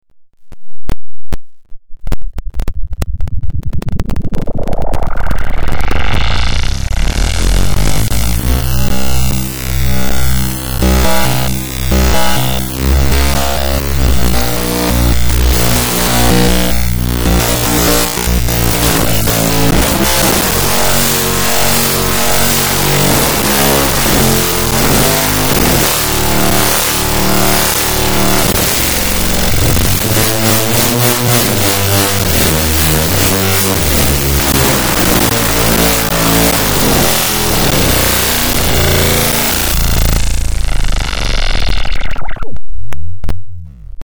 These audio recordings were recorded by other software while CodeSimian played audio on the speakers.
Here are some sounds made with equations + moving the mouse and clicking its buttons.
download Electric Sound 1
Copy/paste the above code into CodeSimian.JAR to play simulated electric guitar with your mouse.